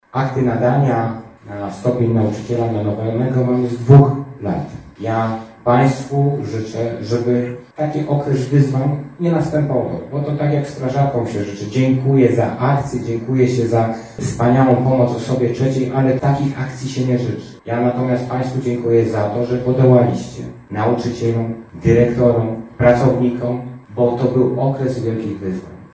Uroczystość odbyła się w sali widowiskowej Międzyrzeckiego Ośrodka Kultury.